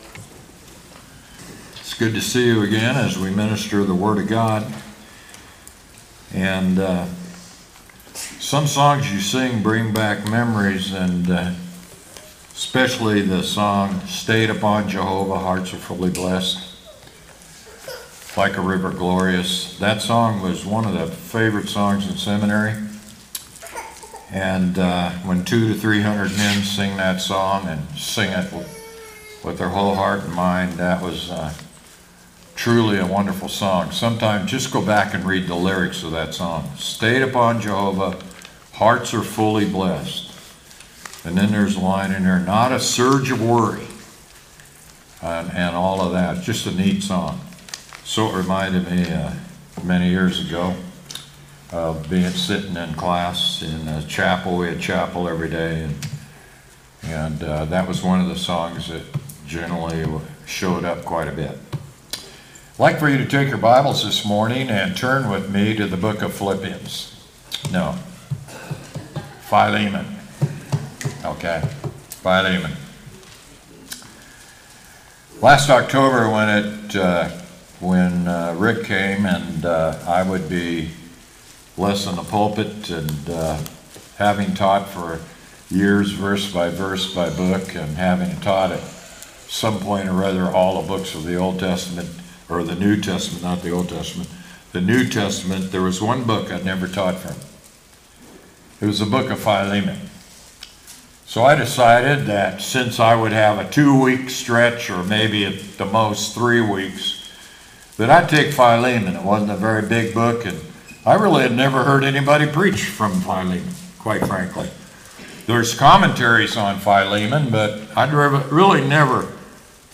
sermon-8-3-25.mp3